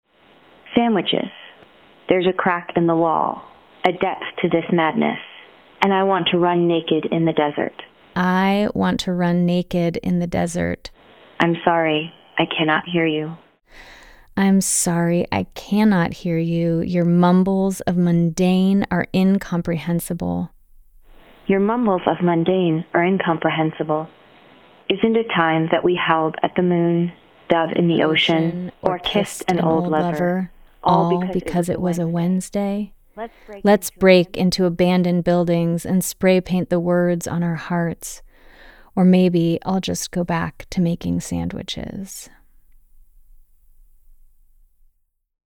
(and her AI voice via Descript.) This slightly remixed version